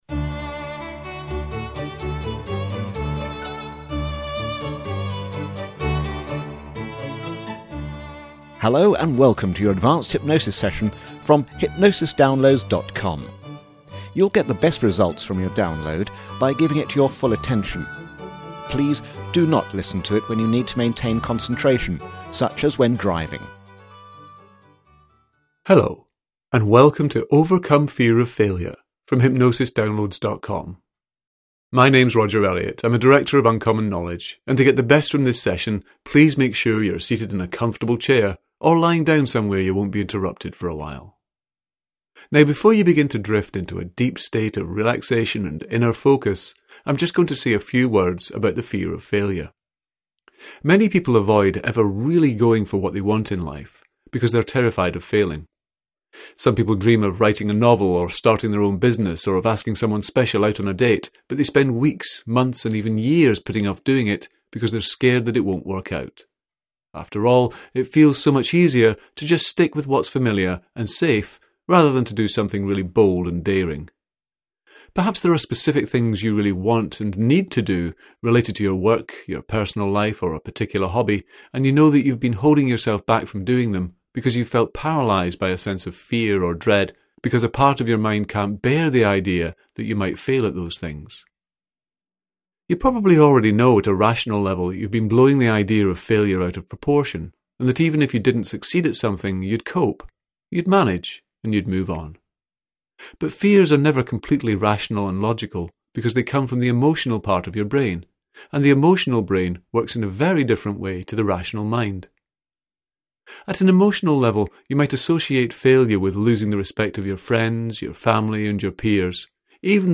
Overcome Fear of Failure Self-Hypnosis Audio Program